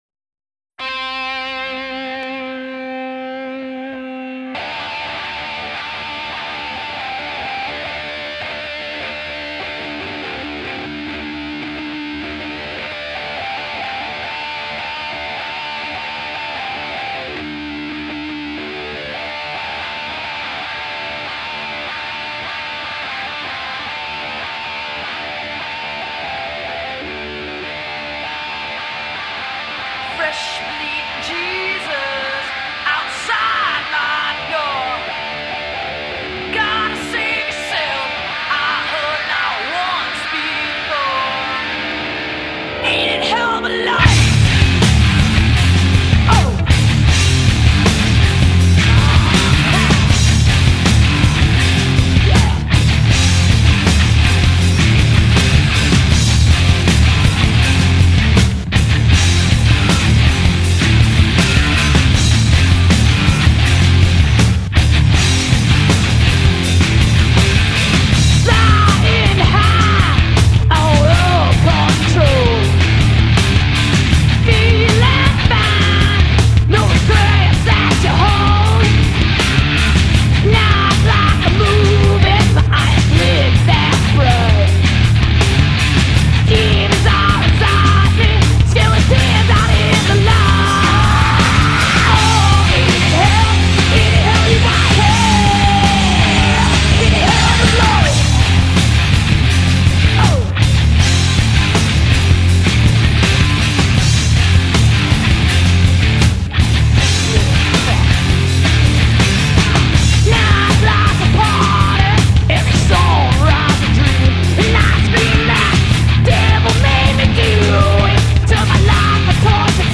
STONER DAGLI USA!!!
the all-femme rock-outfit